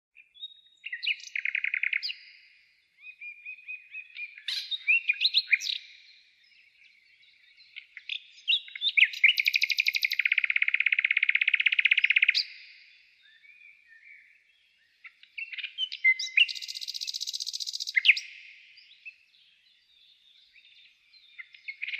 Звуки восхода солнца
Пение первых птиц, тихий шепот ветра в листве, отдаленные голоса природы — все это создает ощущение пробуждения и гармонии.
Пение птиц на рассвете